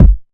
• Bassy Bass Drum One Shot G Key 212.wav
Royality free bass drum single shot tuned to the G note. Loudest frequency: 99Hz
bassy-bass-drum-one-shot-g-key-212-dKZ.wav